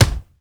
punch_general_body_impact_01.wav